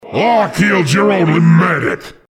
Extracted with GCFScape and WinRAR from the vsh_outburst.bsp.
This is an audio clip from the game Team Fortress 2 .